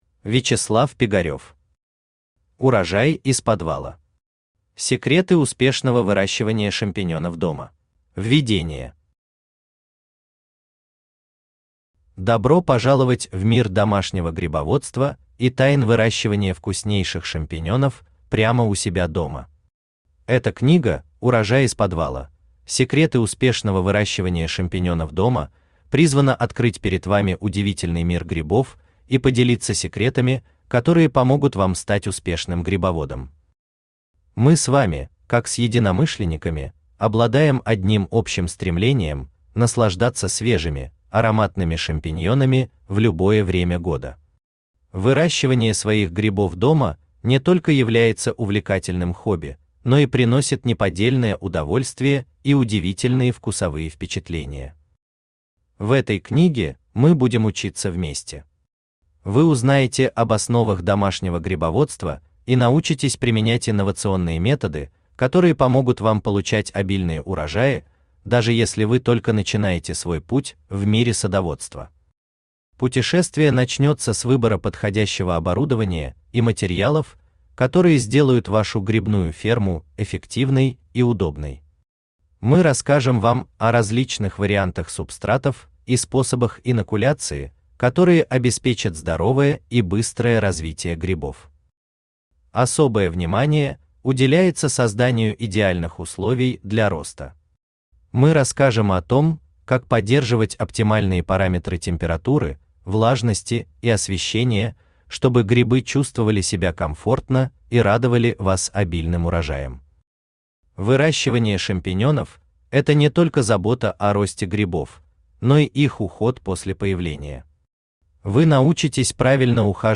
Аудиокнига Урожай из подвала. Секреты успешного выращивания шампиньонов дома | Библиотека аудиокниг
Секреты успешного выращивания шампиньонов дома Автор Вячеслав Пигарев Читает аудиокнигу Авточтец ЛитРес.